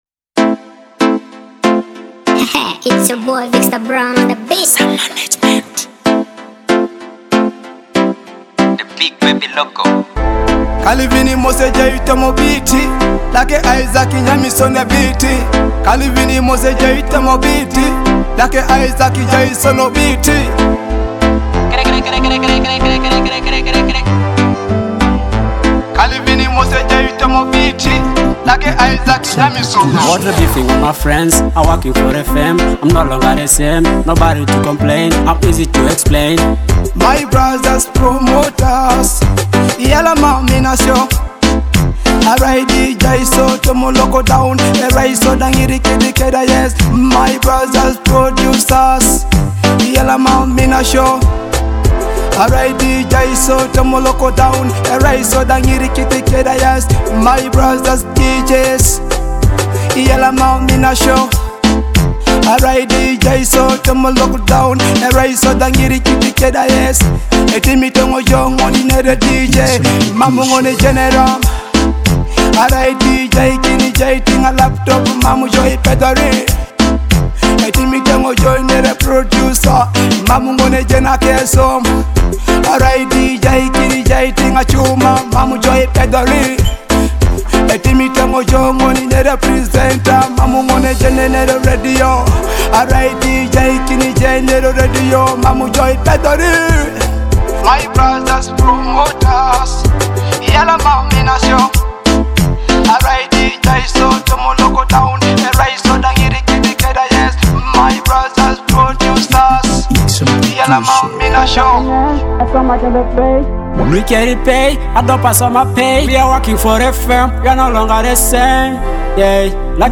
a high-energy track